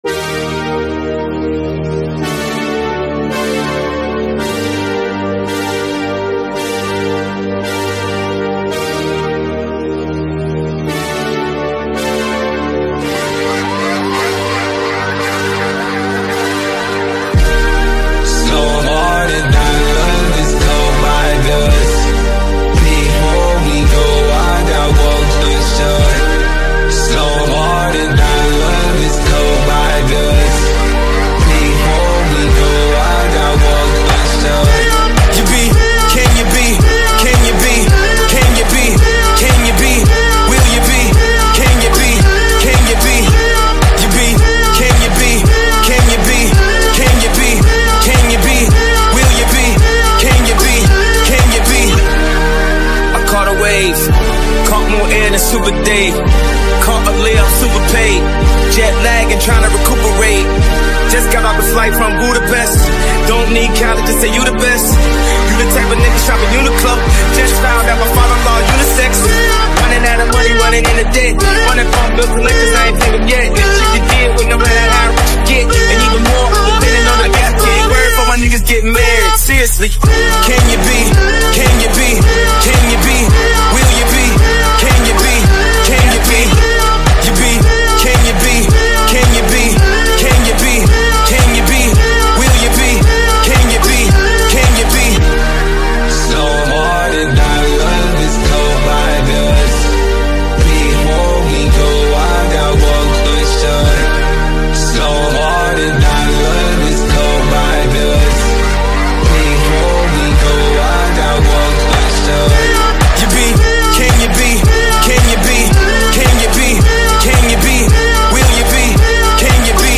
Hip-Hop Rage